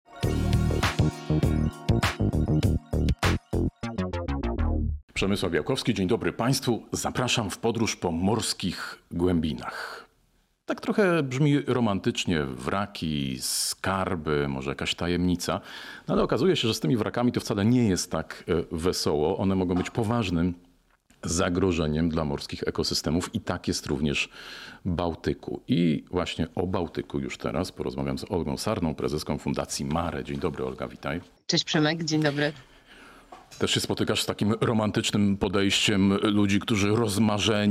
Cała rozmowa jest dostępna w serwisie Zielona Interia.